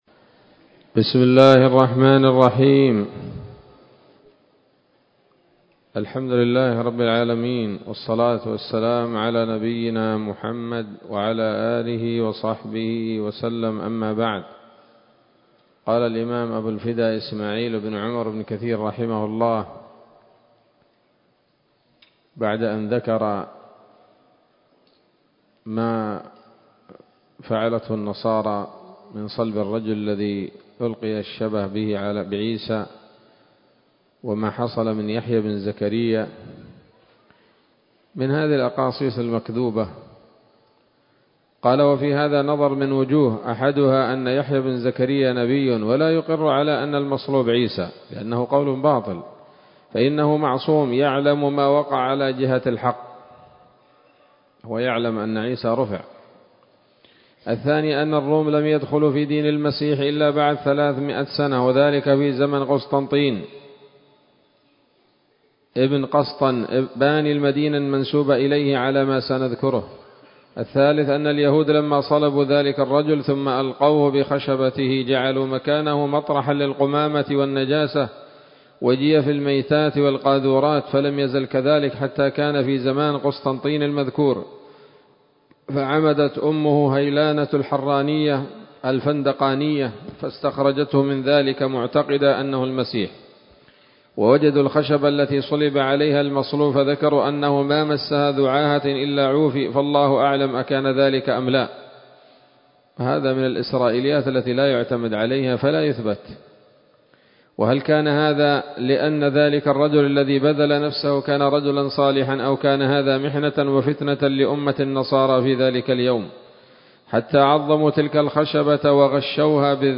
‌‌الدرس الثالث والخمسون بعد المائة من قصص الأنبياء لابن كثير رحمه الله تعالى